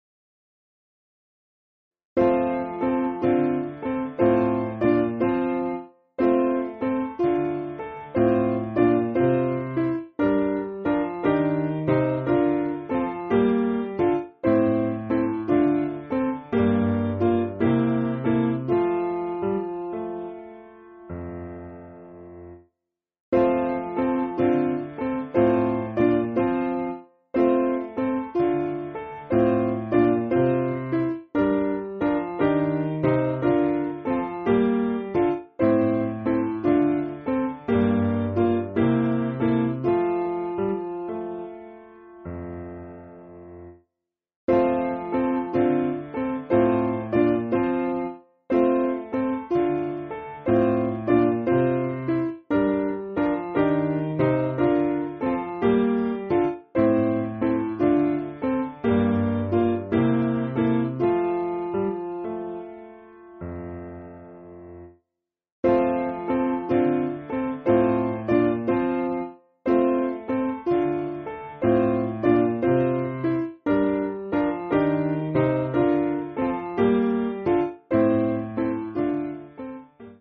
Simple Piano
(No Chorus)